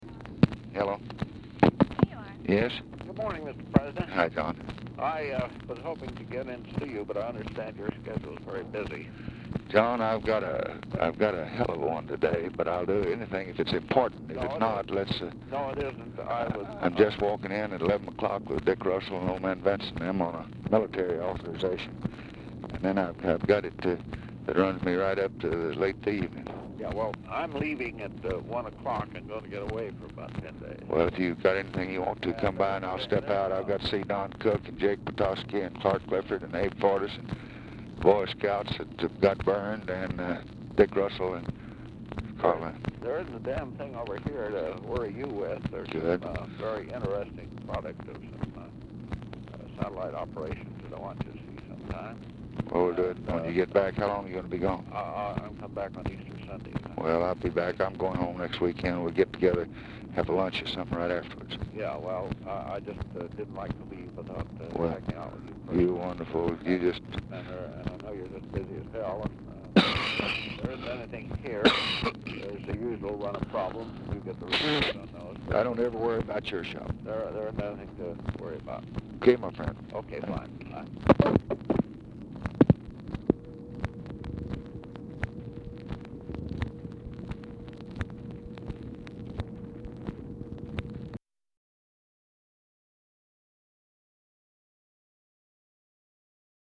Telephone conversation
BELT IS TORN ALONG CREASE, RESULTING IN POOR SOUND QUALITY AT TIMES
Format Dictation belt
Oval Office or unknown location